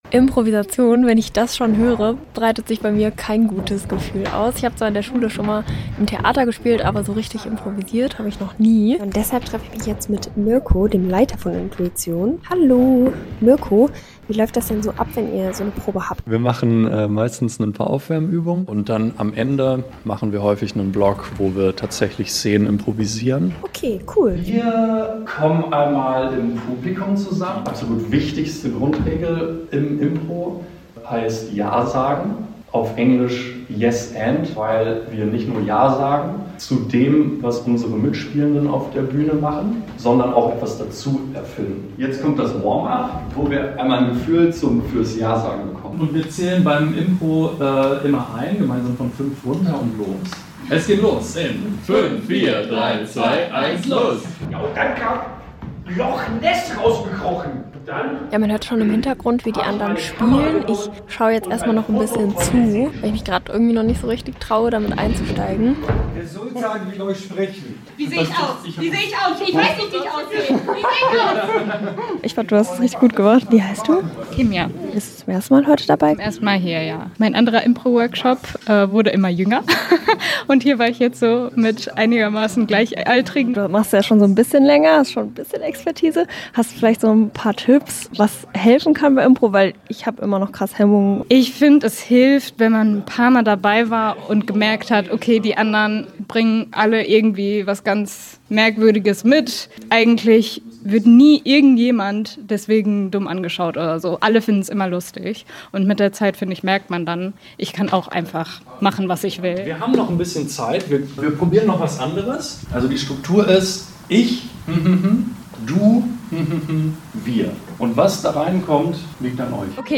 Serie: Reportage